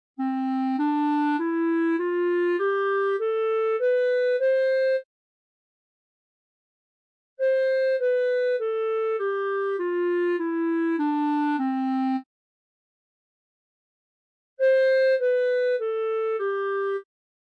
Osserviamo: Ascoltiamo le tre scale in successione: scale_01.mp3
scale_01.mp3